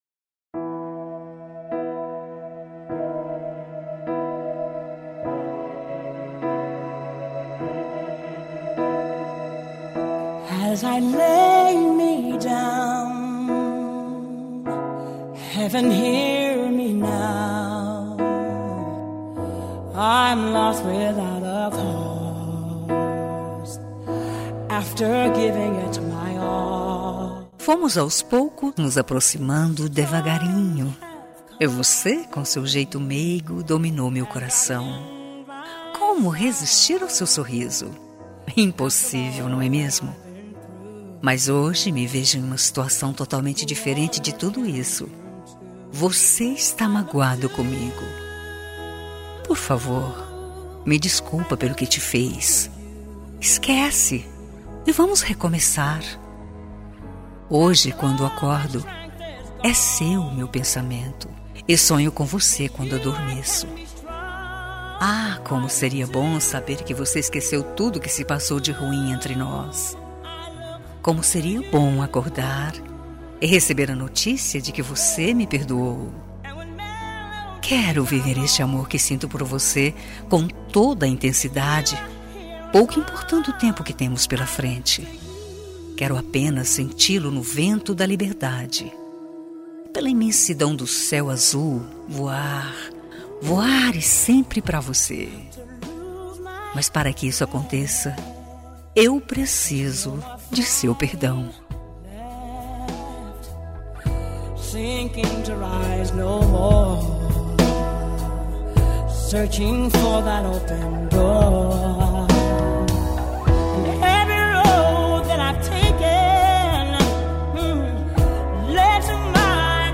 Telemensagem de Desculpas – Voz Feminina – Cód: 355